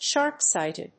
アクセントshárp‐síghted